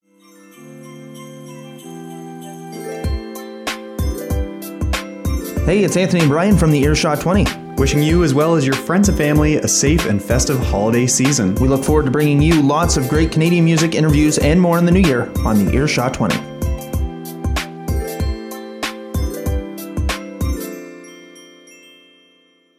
A Christmas Station ID from !earshot 20
Recording Location: Local 107.3FM (CFMH) - Saint John, New Brunswick
Type: PSA
0kbps Stereo